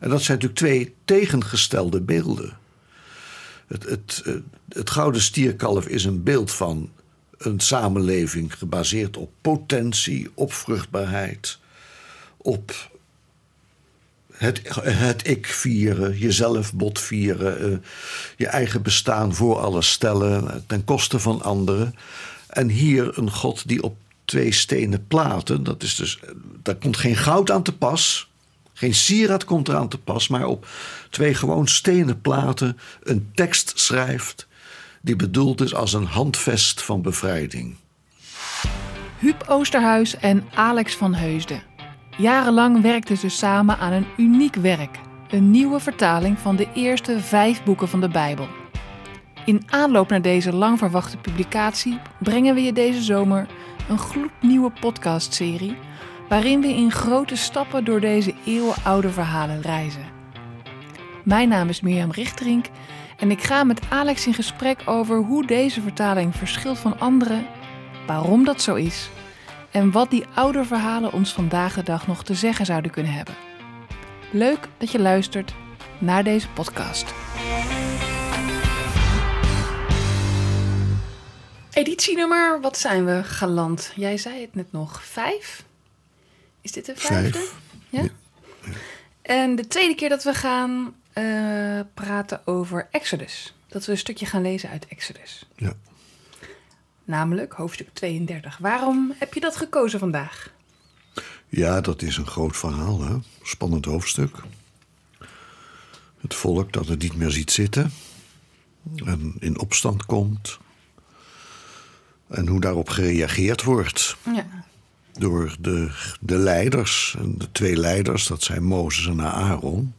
In de podcast hoor je ook dit lied van Huub Oosterhuis (muziek: Antoine Oomen) Hoe ver te gaan: Hoe ver te gaan?